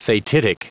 Prononciation du mot : fatidic